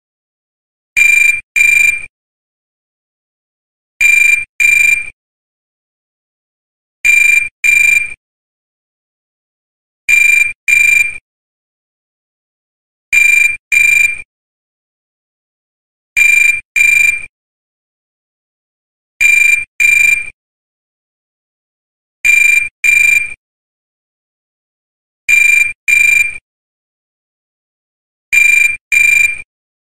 Suoneria Classical Dial
Sound Effects
old , telephone , nokia , phone , bleep , vieux ,